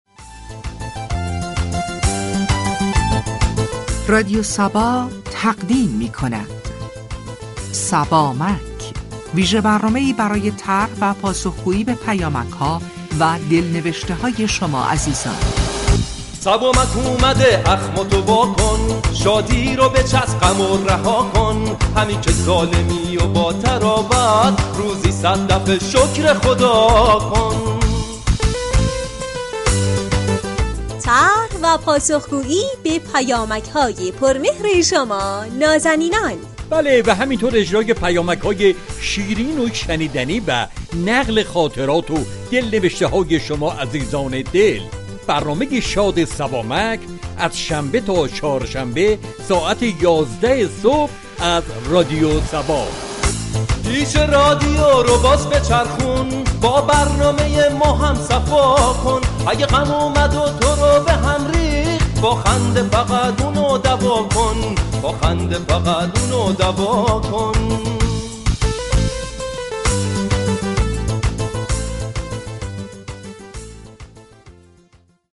روز چهارشنبه سوم آذر برنامه «صبامك » درباره خنده درمانی با مخاطبان صحبت می كند،این برنامه از طریق پیامك خوانی و پاشخ پیامك های شنوندگان با شوخ طبعی شما را با فواید خنده درمانی، روش‌های ایجاد فرصت خندیدن، راه‌های تقویت حس شوخ طبعی و استفاده از خنده برای غلبه بر مشكلات آشنا می كند.